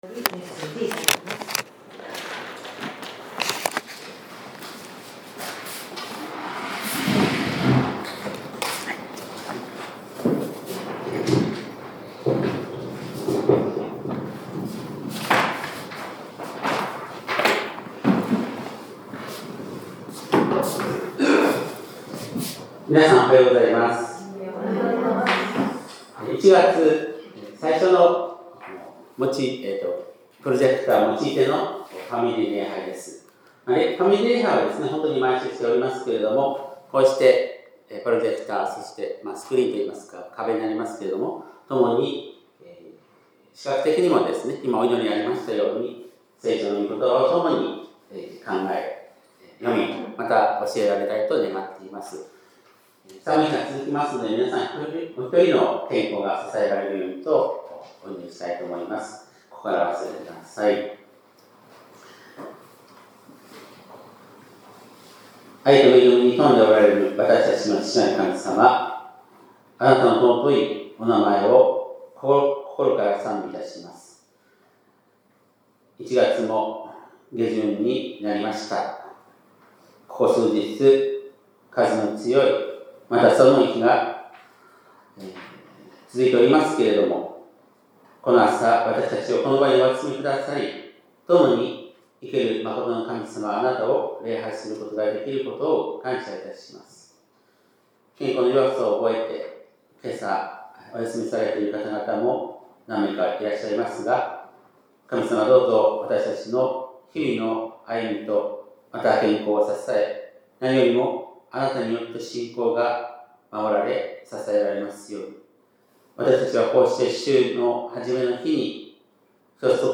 2026年1月25日（日）礼拝メッセージ